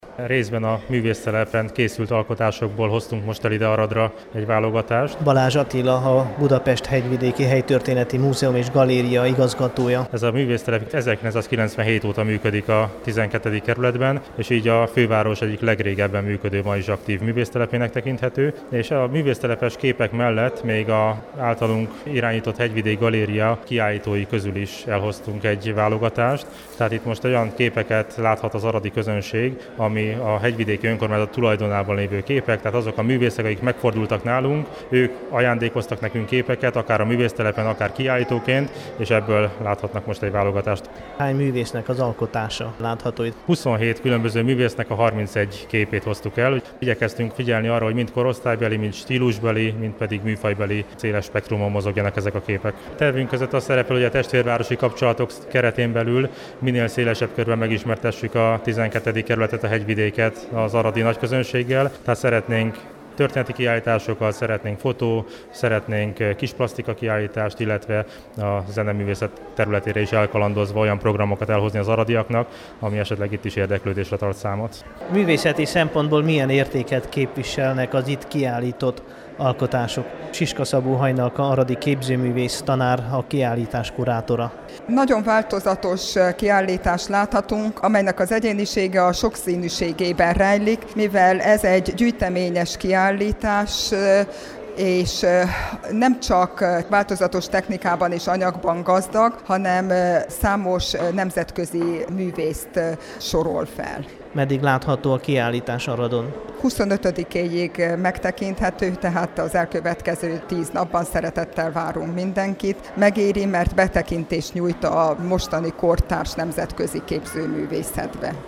budapest-hegyvideki_valogatas-kiallitas.mp3